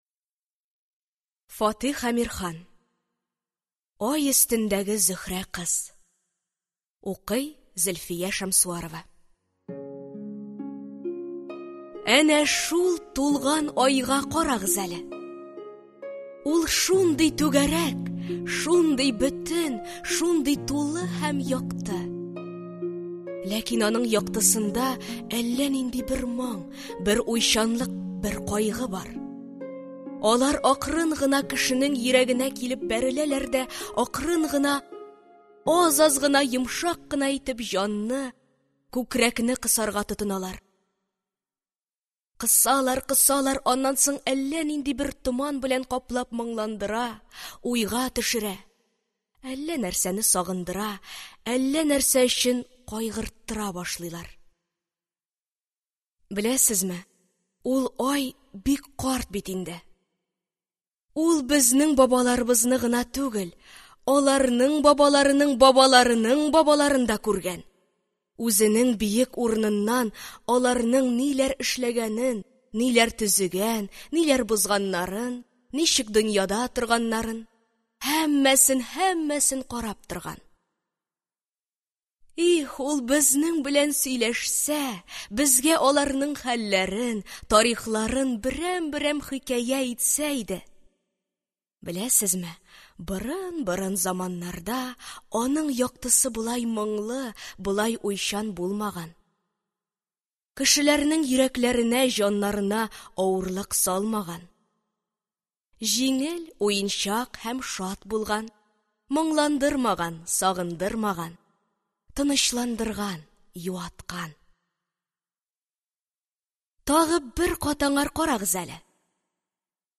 Аудиокнига Ай өстендәге Зөһрә кыз | Библиотека аудиокниг